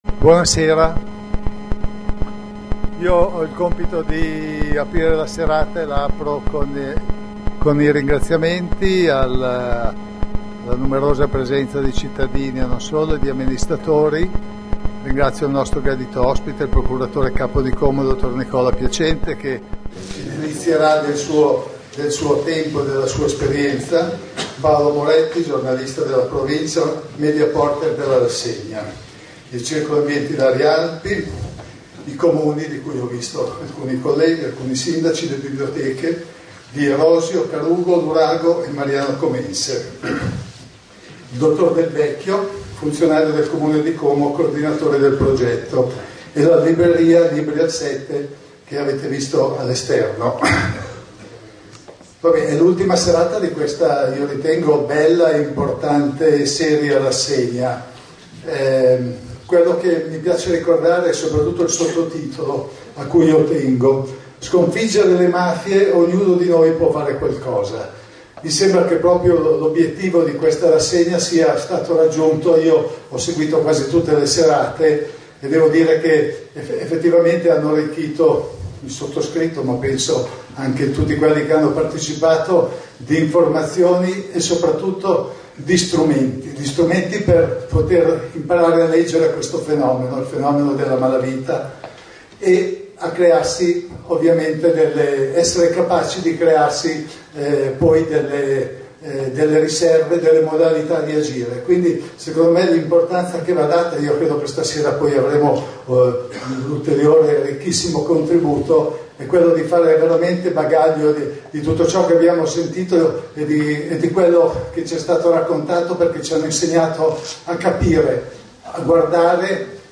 Inverigo, ore 21:00 Piccolo Teatro di S.Maria
Incontro con il magistrato Nicola Piacente attuale Procuratore Capo di Como, esperto nella lotta alla criminalità organizzata e al terrorismo internazionale.